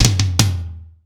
ROOM TOM3C.wav